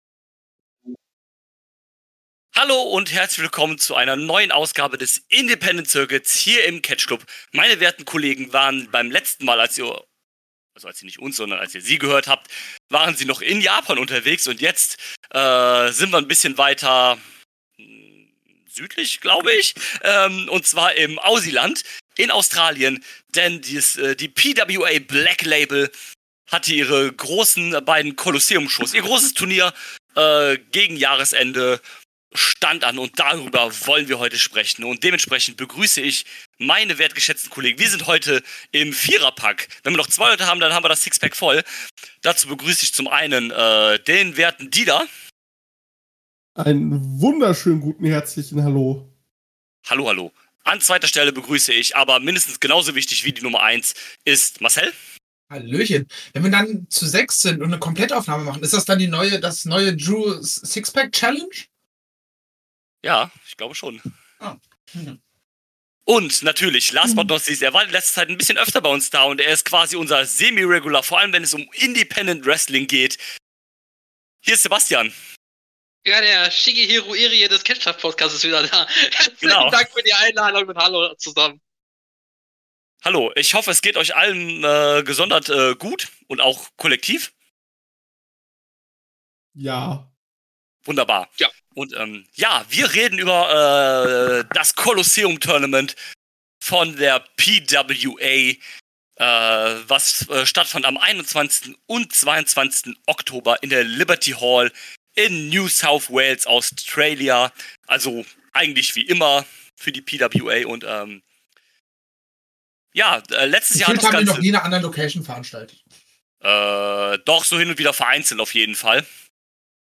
Das Colosseum von PWA stand auf dem Programm! In der Vierer Runde sprechen wir über alle Turnier Matches, Titel Matches und was sonst noch was passierte.